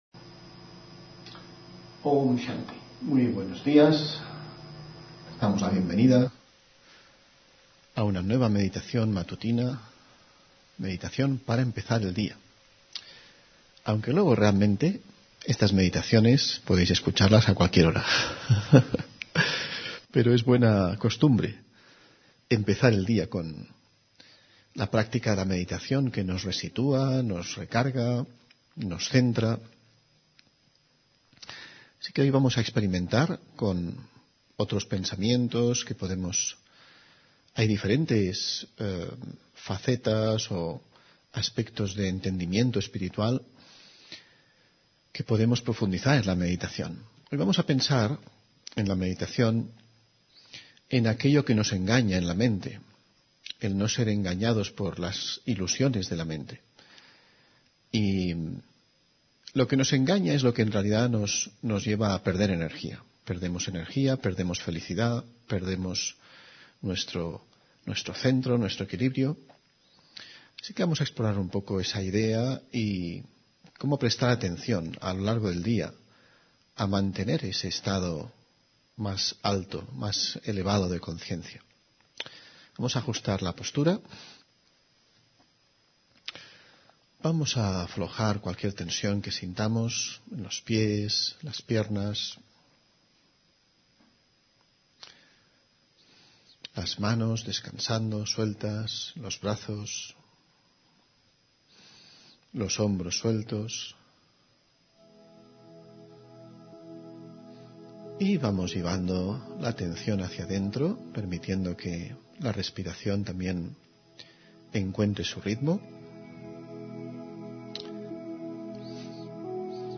Meditación y conferencia: Dar y sentir que no te corresponden (6 Abril 2024)